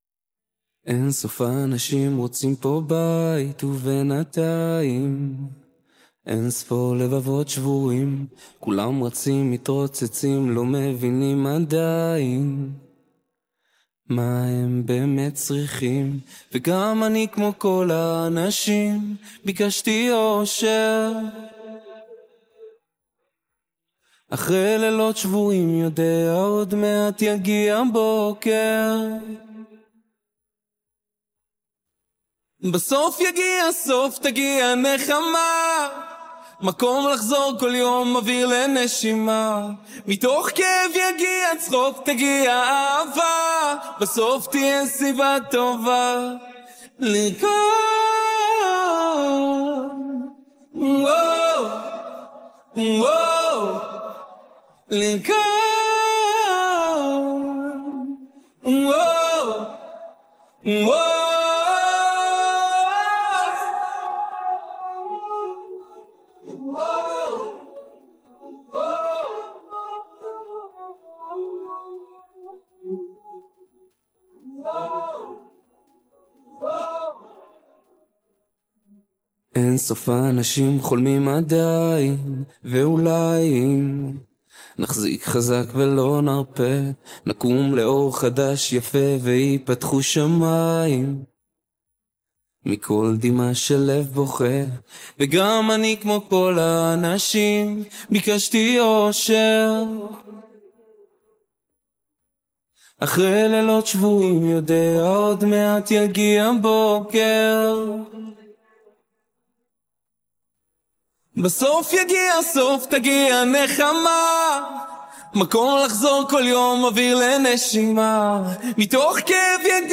אתה יכול לעשות בווקאל רימובר להפריד ולהוריד רק את הווקאל
זה על 122BPM